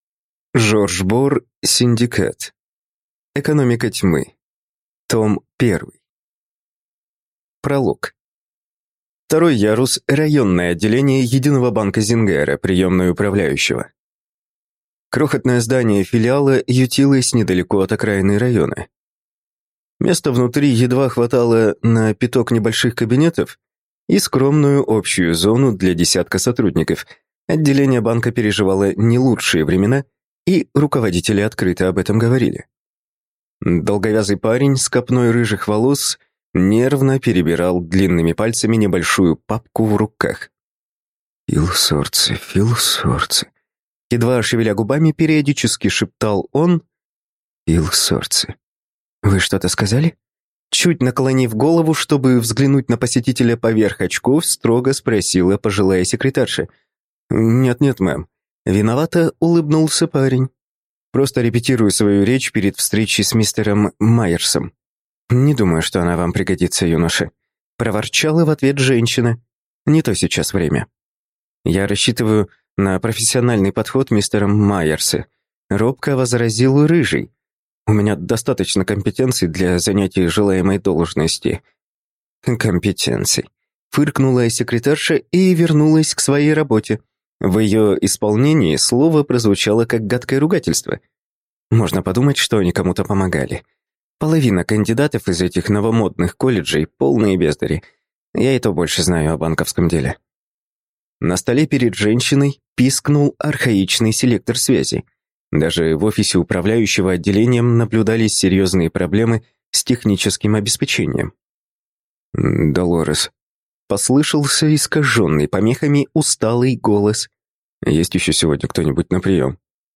Аудиокнига Синдикат. Экономика Тьмы | Библиотека аудиокниг